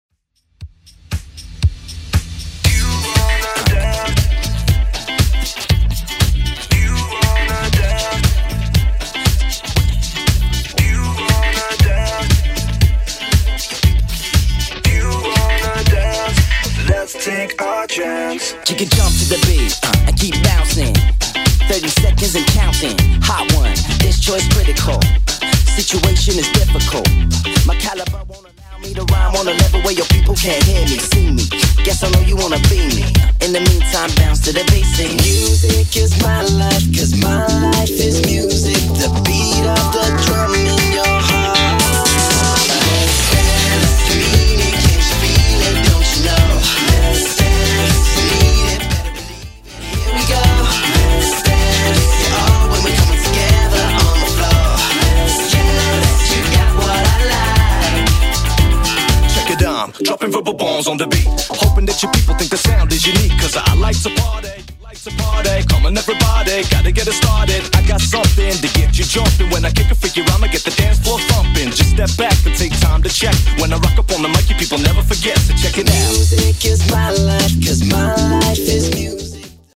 Genre: 80's Version: Clean BPM: 110